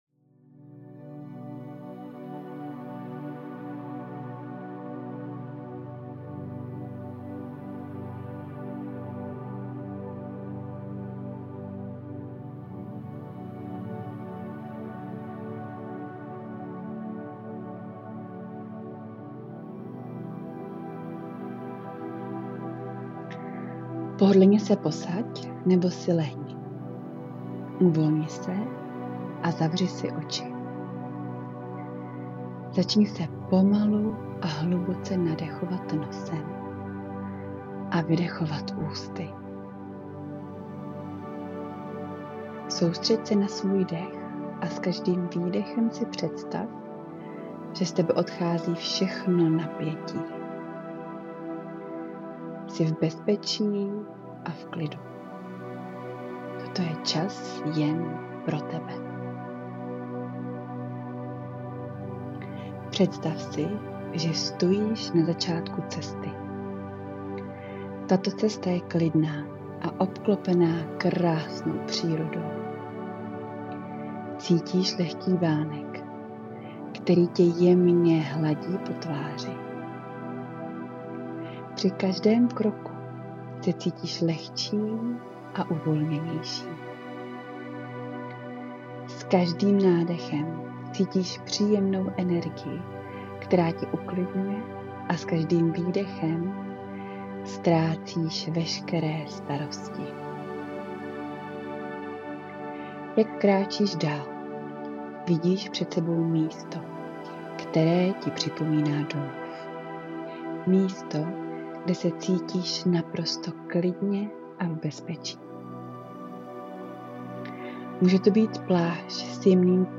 Tudy k meditaci